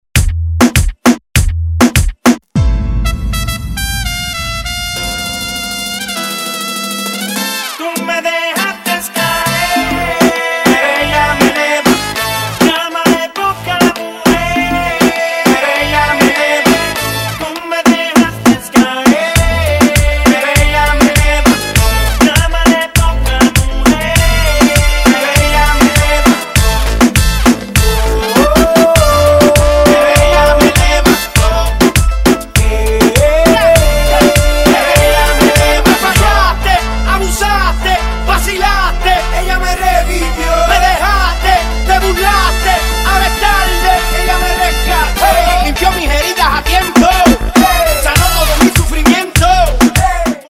Dive into the pulsating rhythms and energy of Latin music